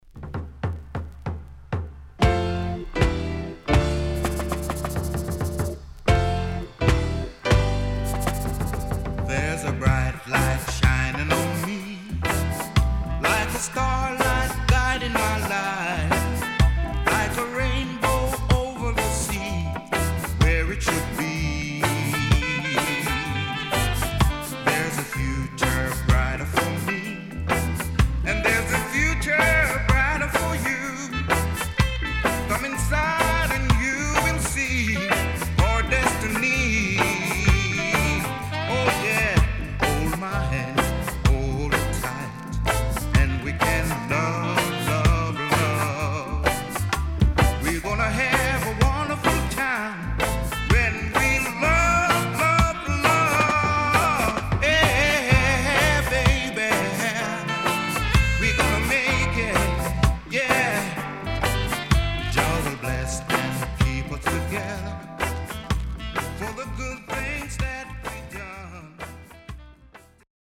W-Side Good Vocal. 両面後半Dub接続 Good Condition
SIDE A:少しチリノイズ入りますが良好です。